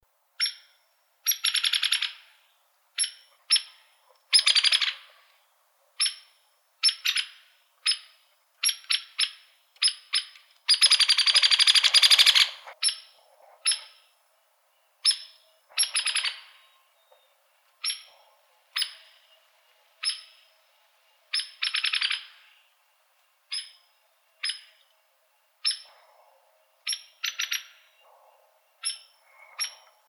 hairywoodpecker.wav